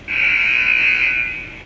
buzzer.wav